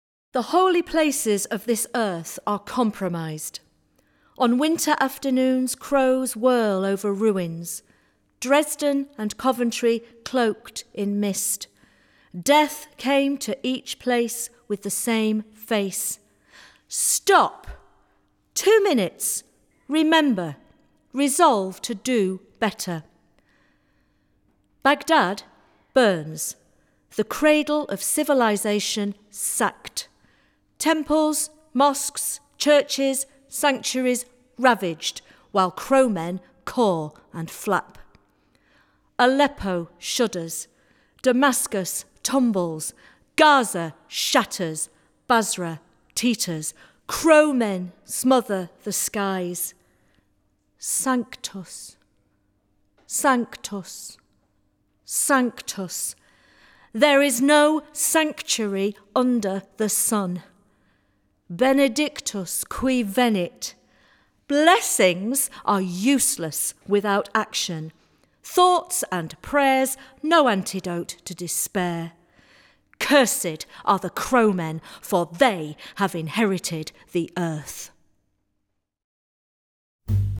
Recorded at Craxton Studios, May 12, 2019
Jazz and poetry commemorating the end of The First World War